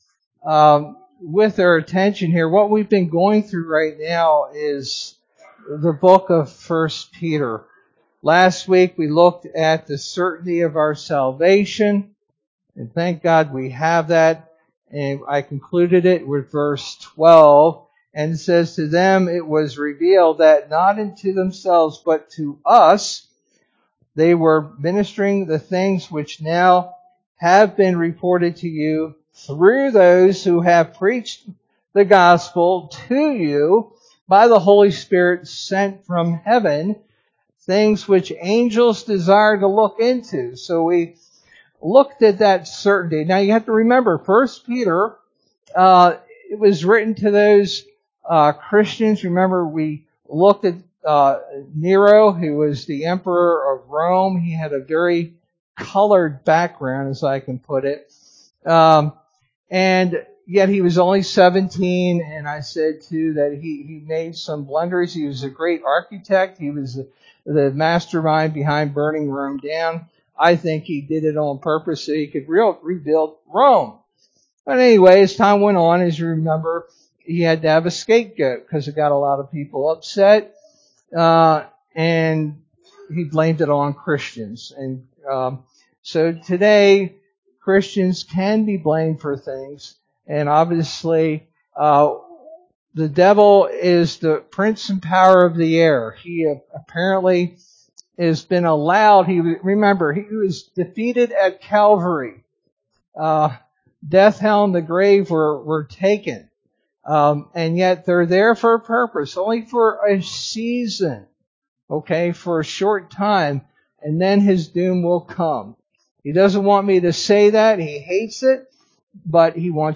Sermon verse: 1 Peter 1:13-2:3